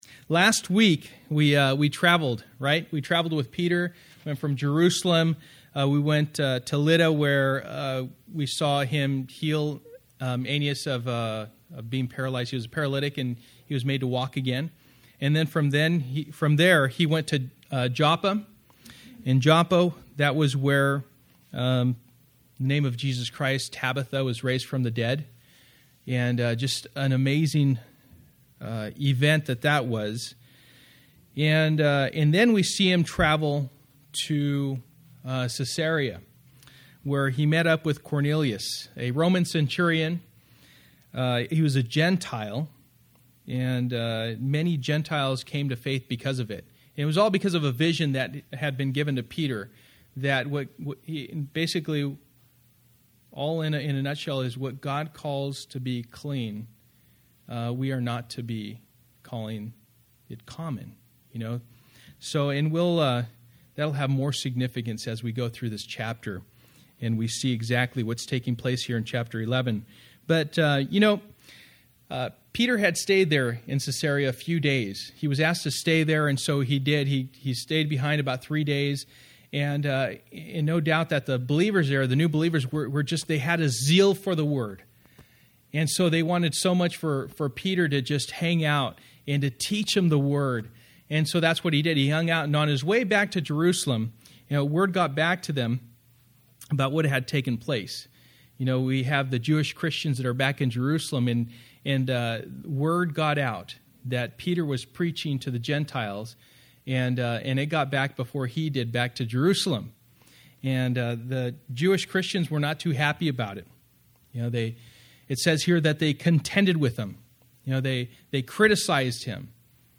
Acts Passage: Acts 11:1-30 Service: Wednesday Night %todo_render% « Authentic